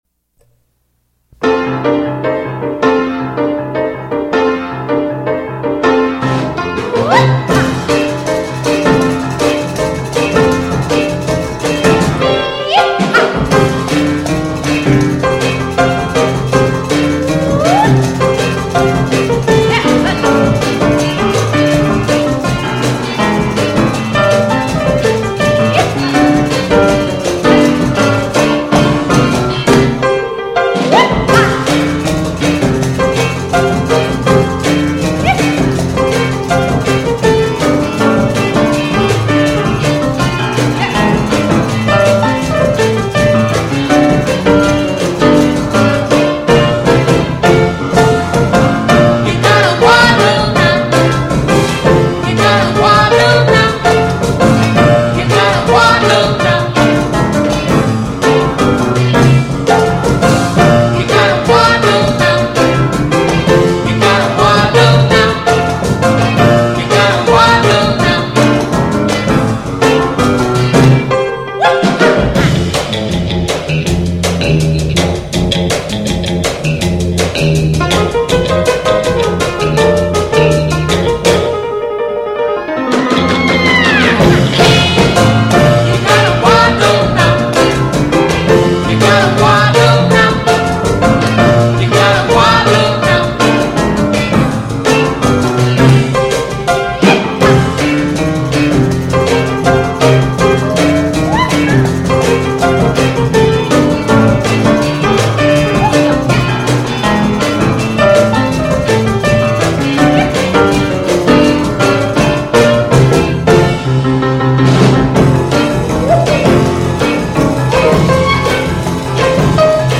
Piano Jazz Quintet